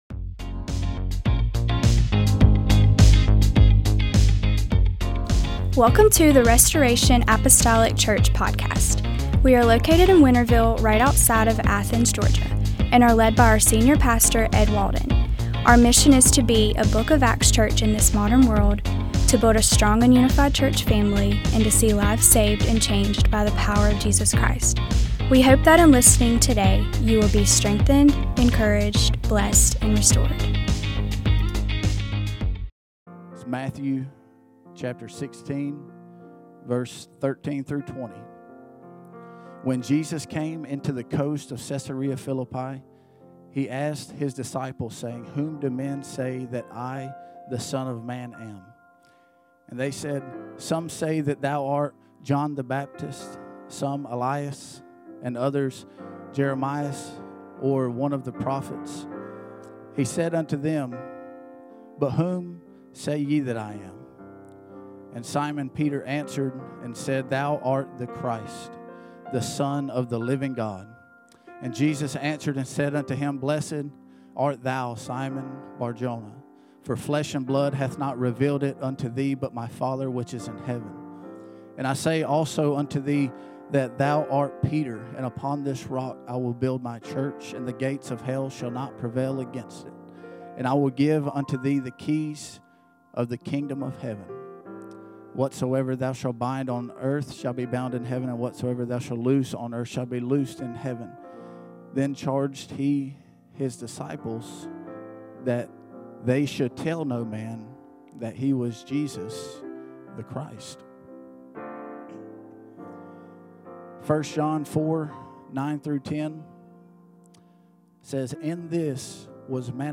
Restoration Apostolic Church The Son of God Nov 16 2025 | 00:43:12 1x 00:00 / 00:43:12 Subscribe Share Apple Podcasts Spotify Overcast RSS Feed Share Link Embed